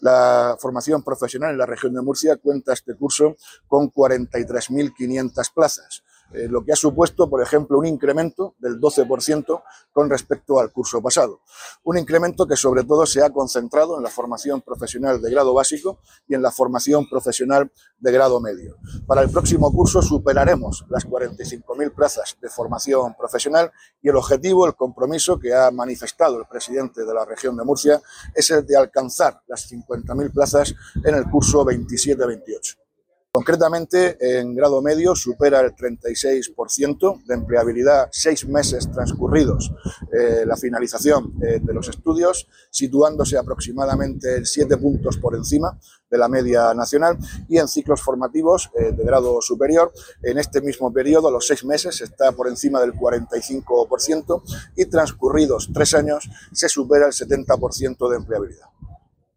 Abrir o Descargar archivo Declaraciones del consejero de Educación y Formación Profesional, Víctor Marín, sobre la oferta de Formación Profesional en el Noroeste